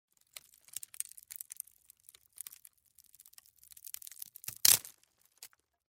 Звуки хруста веток
На этой странице собрана коллекция качественных звуков хруста и треска веток.